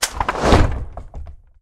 Звуки катапульты: Огненный снаряд вылетел из катапульты